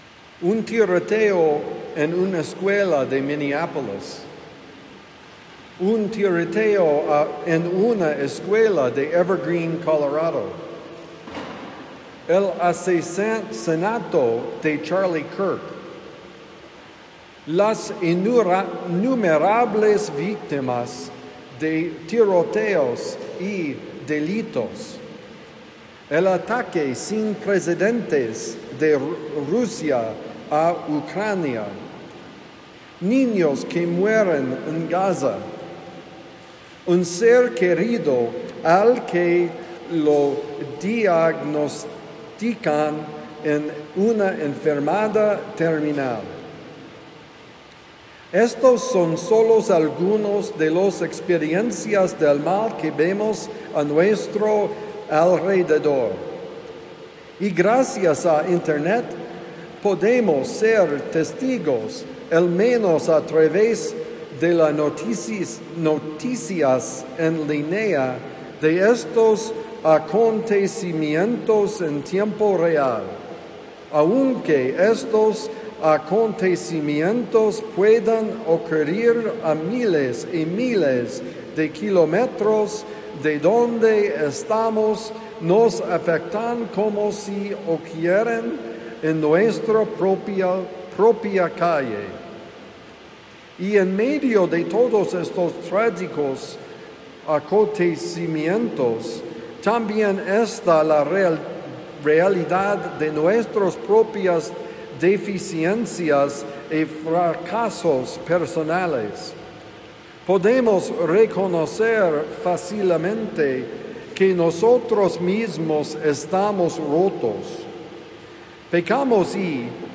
El problema del mal: Homilía para el domingo 14 de septiembre de 2025 – The Friar